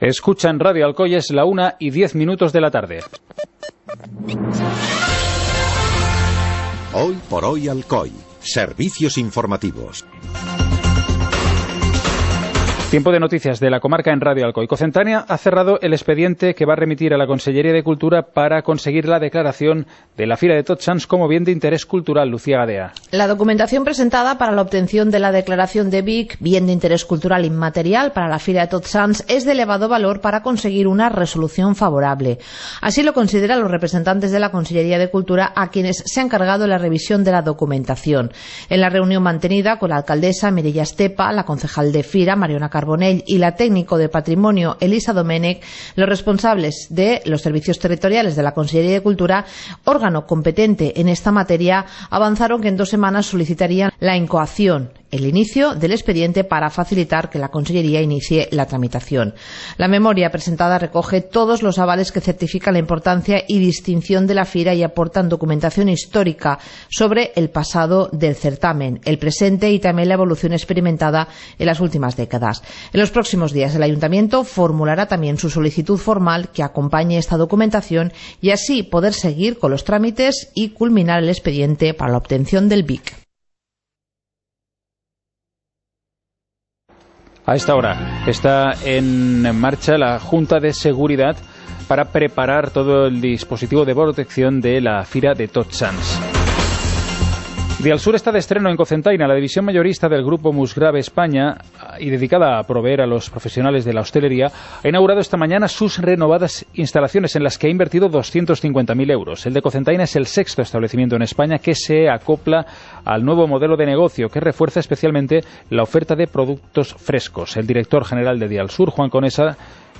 Informativo comarcal - jueves, 05 de octubre de 2017